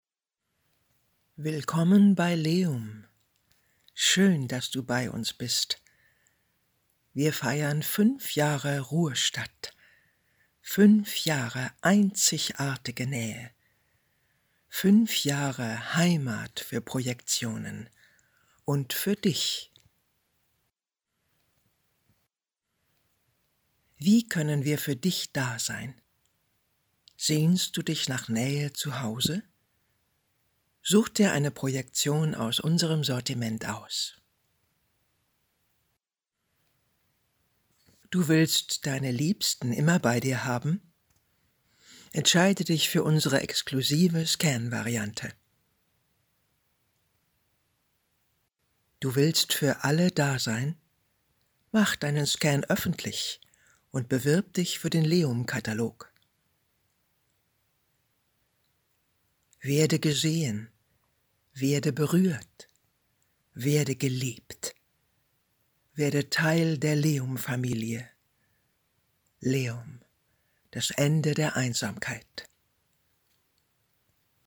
markant
Alt (50-80)
Audio Drama (Hörspiel), Commercial (Werbung), Game, Off, Scene